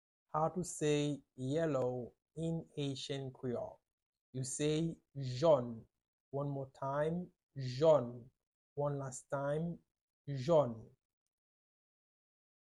Listen to and watch “jòn” audio pronunciation in Haitian Creole by a native Haitian  in the video below:
22.How-to-say-Yellow-in-Haitian-Creole-–-jon-with-Pronunciation.mp3